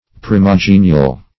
Meaning of primogenial. primogenial synonyms, pronunciation, spelling and more from Free Dictionary.
Search Result for " primogenial" : The Collaborative International Dictionary of English v.0.48: Primogenial \Pri`mo*ge"ni*al\, a. [See Primigenial .] First born, made, or generated; original; primary; elemental; as, primogenial light.